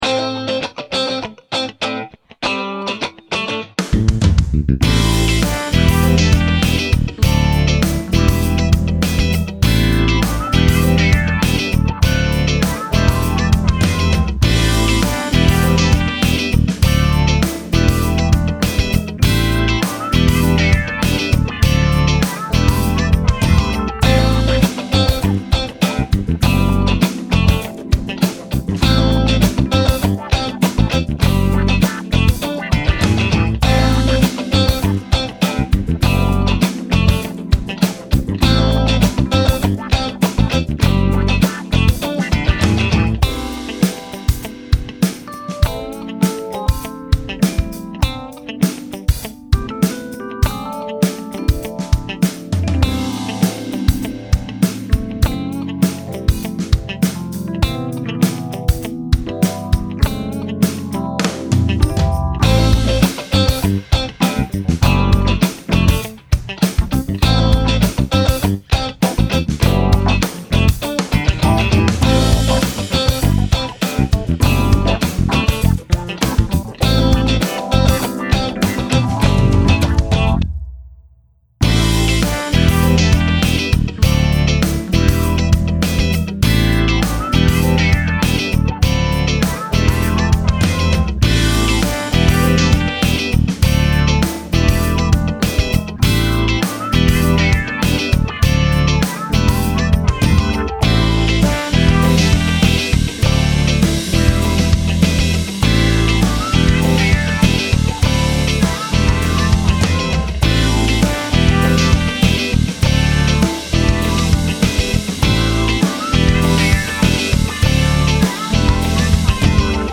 Sheet Music and Backing Tracks
Funk-Beginner.mp3